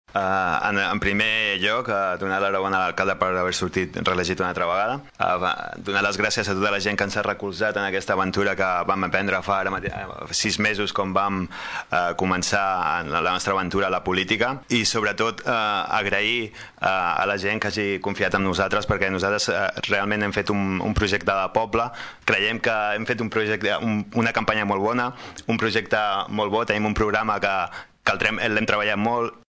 L’auditori del Teatre Clavé s’ha omplert de gom a gom durant en Ple de constitució del nou ajuntament.